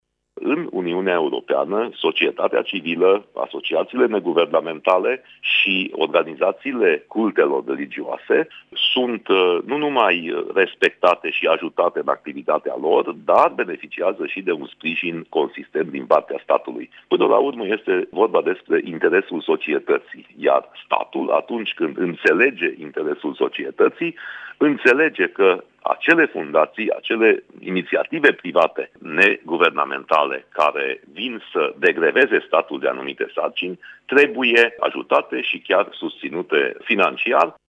Un ajutor important pentru organizațiile și fundațiile care activează în domeniul asistenței sociale și al protecției copilului ar fi sprijinul bănesc din partea statului, a mai spus Iuliu Winkler: